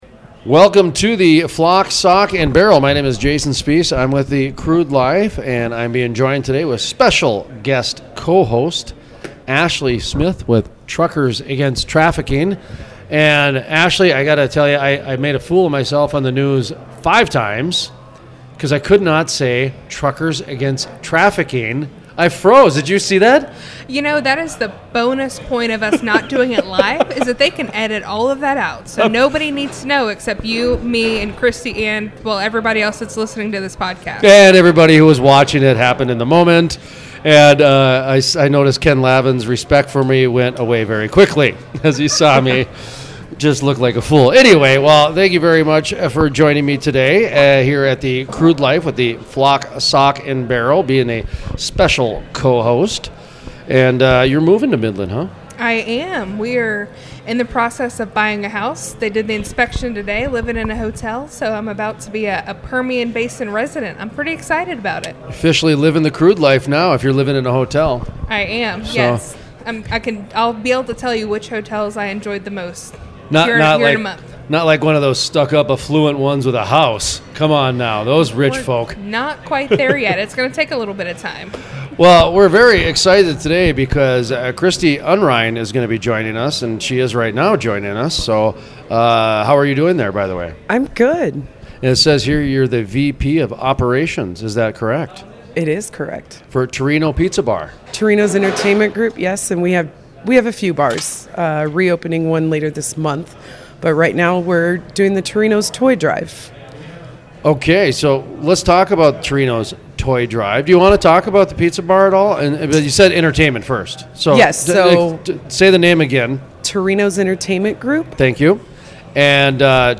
Full Length Interviews Social Causes Unite Industry At Permian Event Play Episode Pause Episode Mute/Unmute Episode Rewind 10 Seconds 1x Fast Forward 10 seconds 00:00 / 14:55 Subscribe Share RSS Feed Share Link Embed